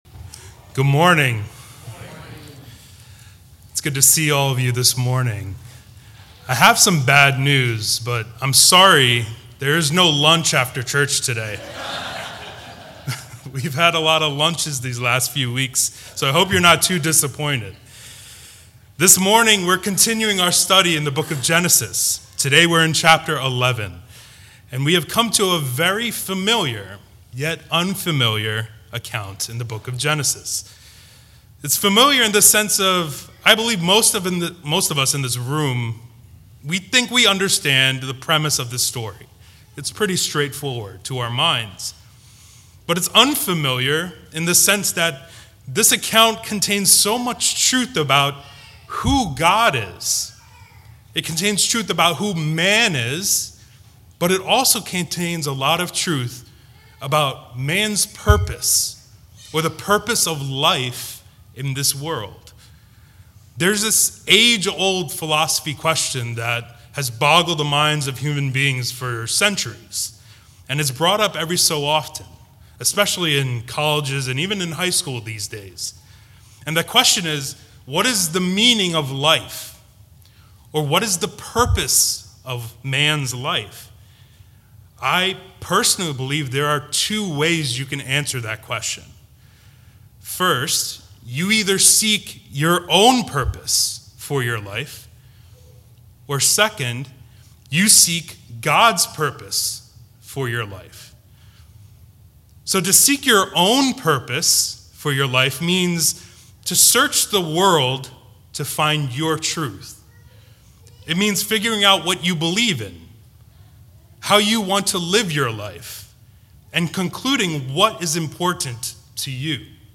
Genesis 11:1-9 | The Tower of Babel – Grace Gospel Chapel
Sermons